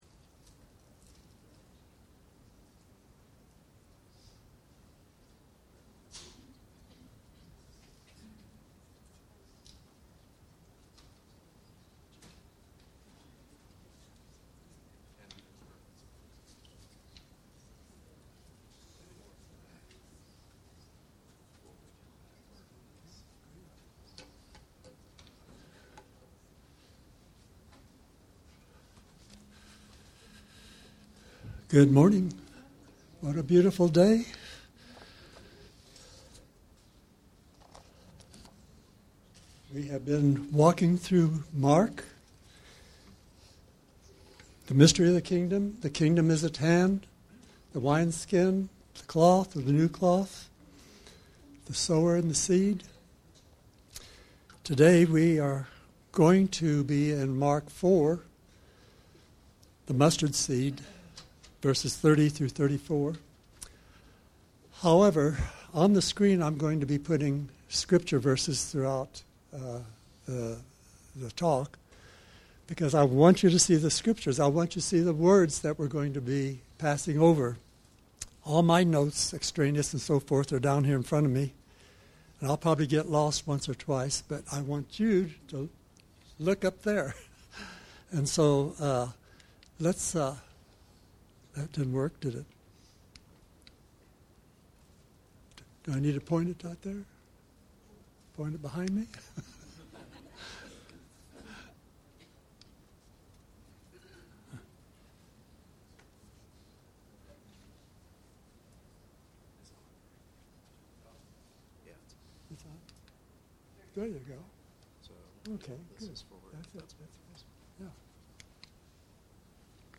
Mark 4:30-34 Service Type: Sunday Morning Mark 4:30-34 Topics